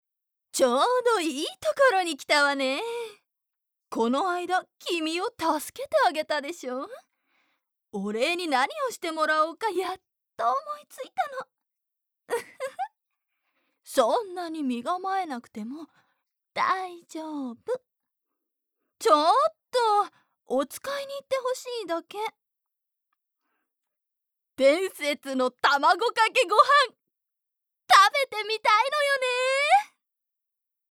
ボイスサンプル
面白いお姉さん